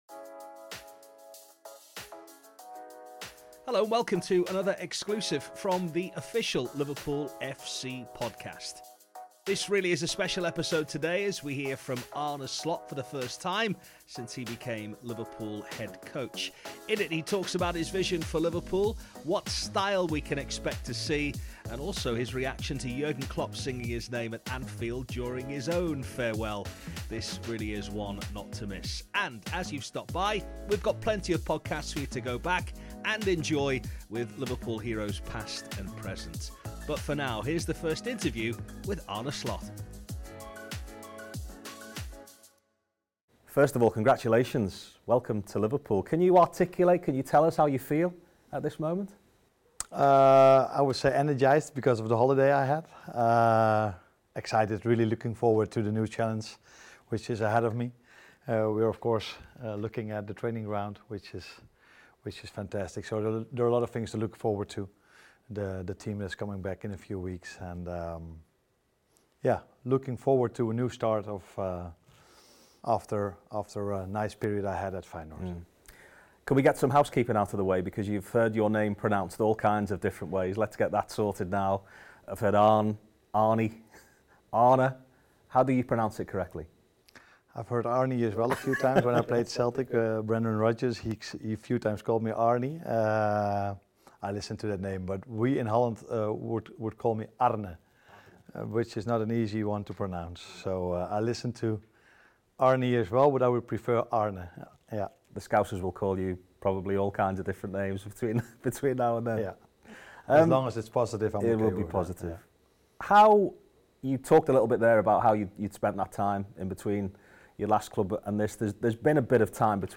Arne Slot - The First Interview
Listen to Arne Slot’s first interview as Head Coach of Liverpool FC.